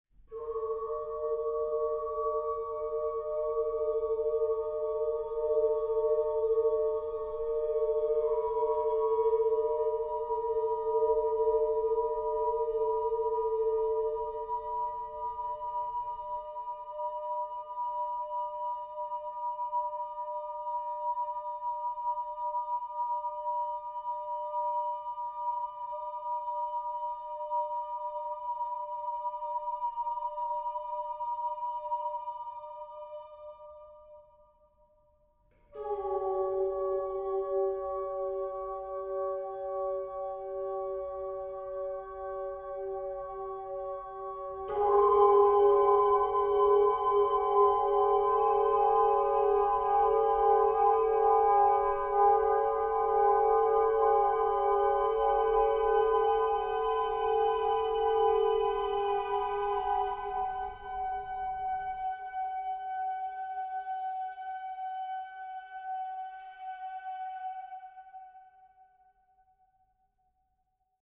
Film music:
- Short choir interlude (2008) (1:11)